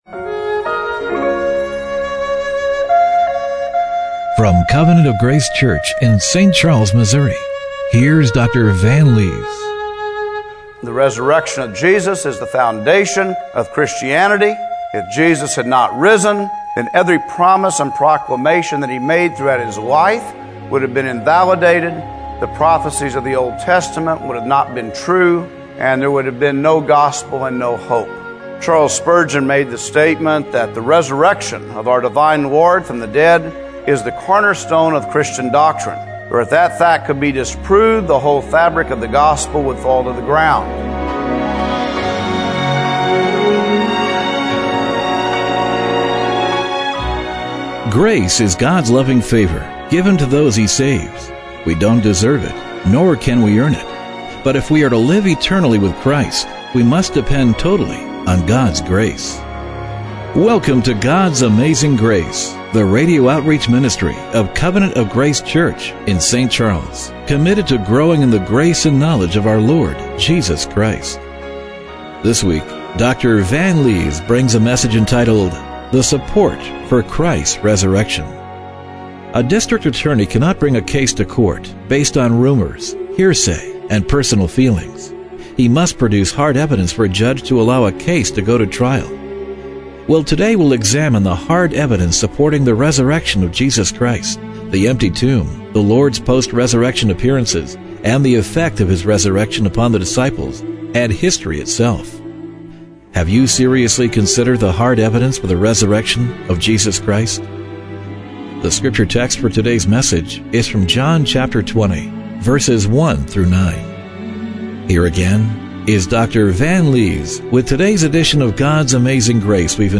John 20:1-9 Service Type: Radio Broadcast Have you seriously considered the hard evidence for the Resurrection of Jesus Christ?